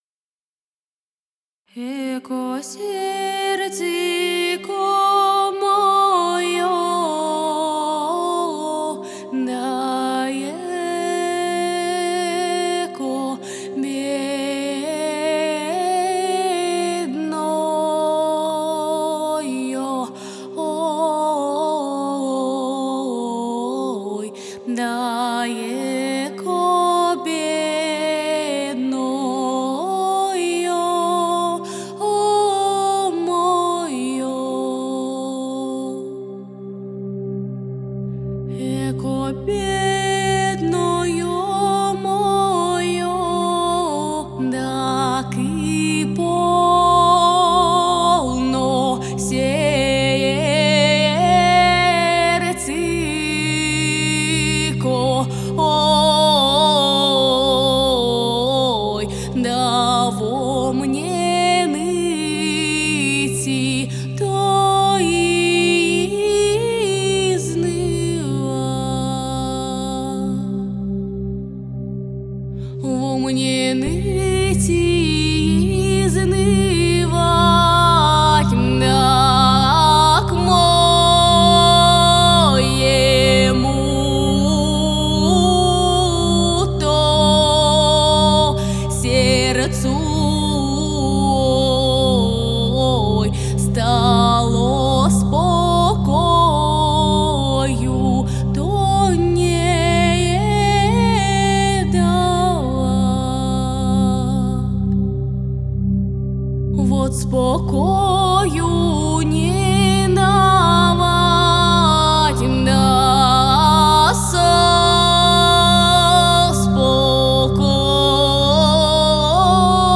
Микрофон для пронзительного женского голоса.
Самое сложное это работать с народниками, особенно в маленьких помещениях. Особенно, если они хотят звучат как ПОП вокалист) Пример сложных условий в моей практике: Вложения Эко серцеко.mp3 Эко серцеко.mp3 5,6 MB · Просмотры: 685